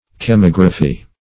Search Result for " chemigraphy" : The Collaborative International Dictionary of English v.0.48: Chemigraphy \Che*mig"ra*phy\, n. [Chemical + -graphy.] Any mechanical engraving process depending upon chemical action; specif., a process of zinc etching not employing photography.